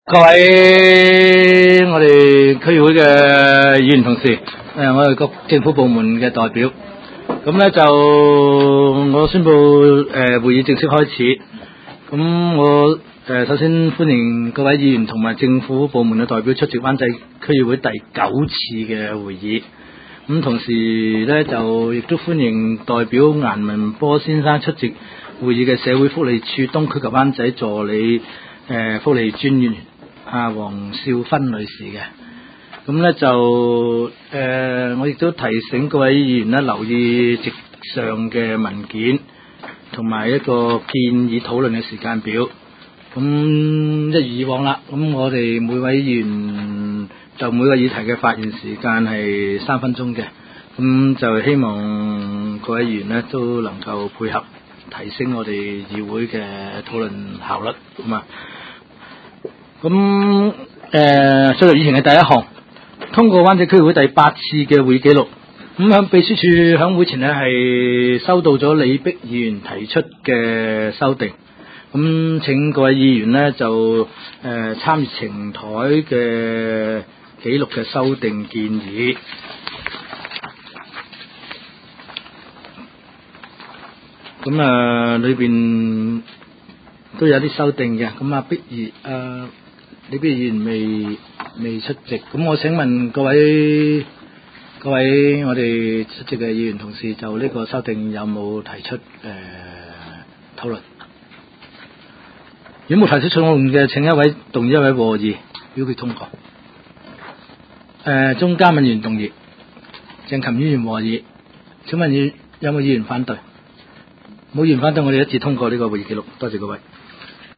区议会大会的录音记录
湾仔区议会会议室